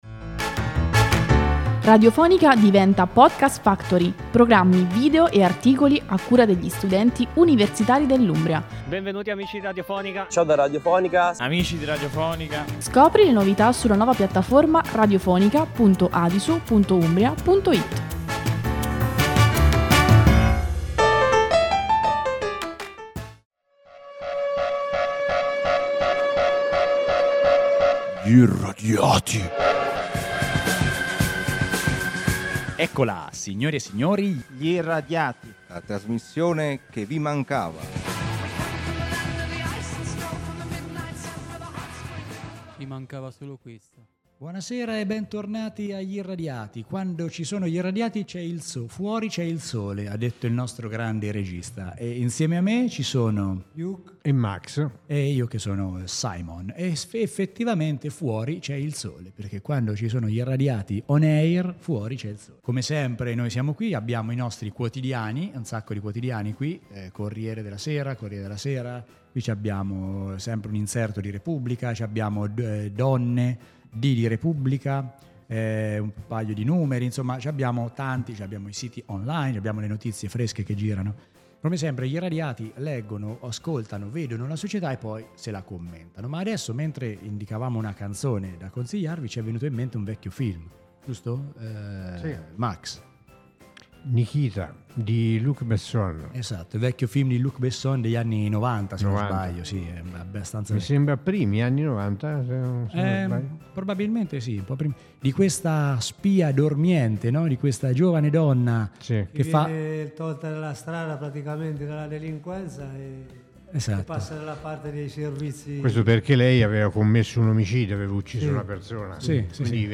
Gli iЯ-radiati: la trasmissione con tanti pensieri e poche parole. Realizzata con effetti speciali che tendono alla normalità.